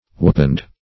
Search Result for " wappened" : The Collaborative International Dictionary of English v.0.48: Wappened \Wap"pened\, a. [Cf. Waped , Wapper .]